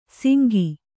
♪ singi